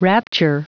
Prononciation du mot rapture en anglais (fichier audio)
Prononciation du mot : rapture